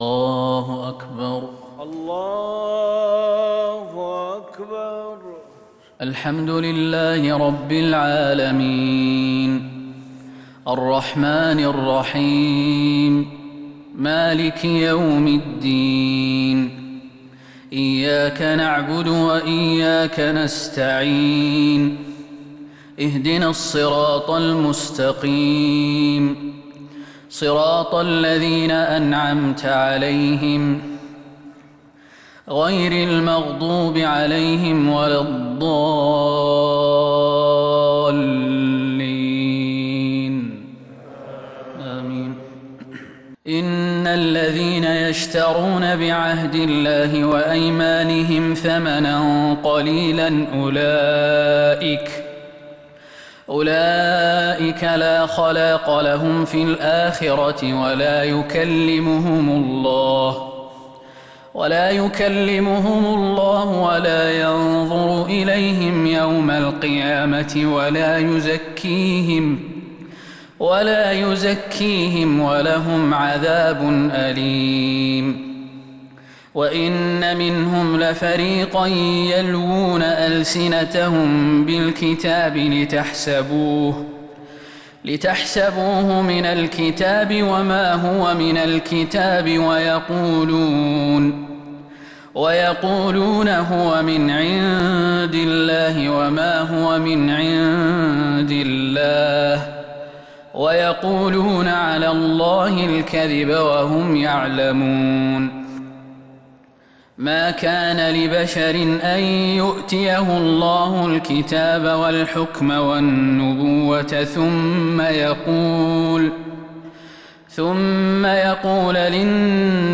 صلاة الفجر للقارئ خالد المهنا 3 ربيع الأول 1442 هـ